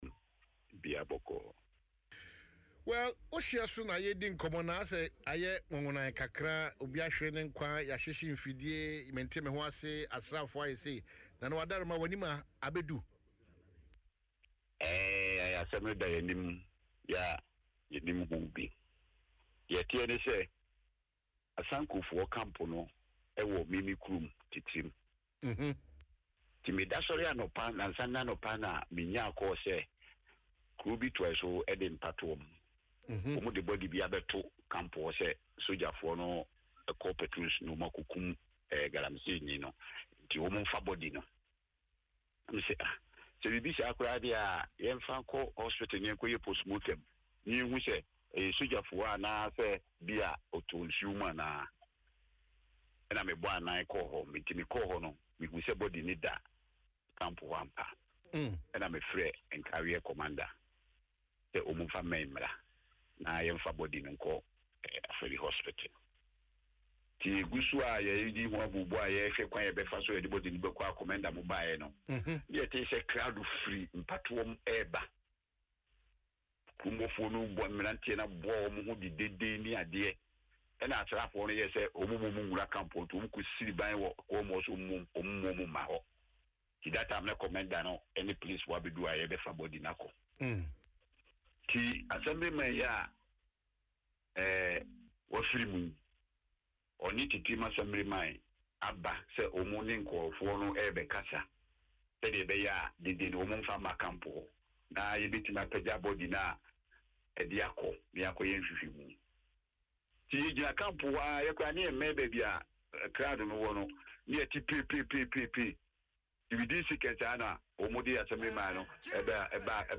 Speaking on Adom FM’s Dwaso Nsem, Nana Akwasi Oko II explained that the unrest began when some youth from Mpatuam dumped the body of a man at the Asanko Mine camp, accusing soldiers guarding the concession of killing him during an anti-galamsey operation.